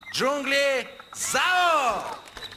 Звук где ведущий и дети кричат джунгли зовут